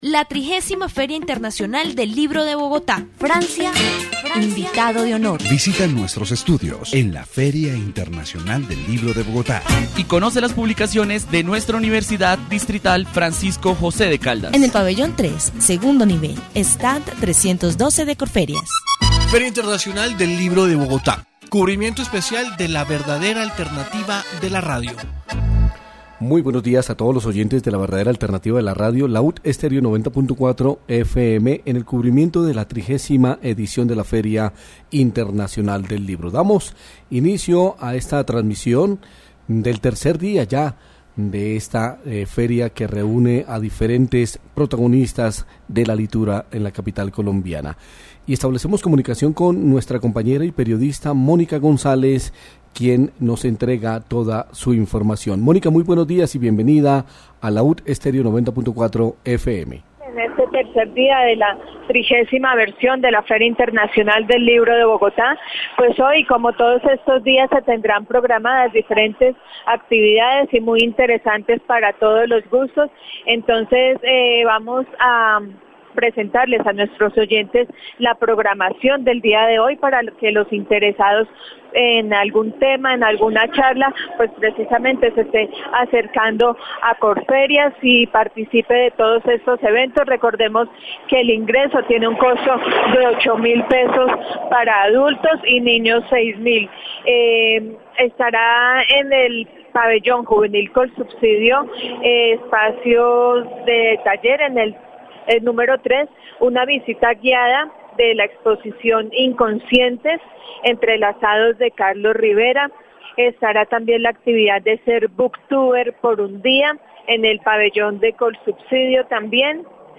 Lectura -- Fomento -- Bogotá (Colombia) , Bogotá (Colombia) -- Grabaciones sonoras , Programas de radio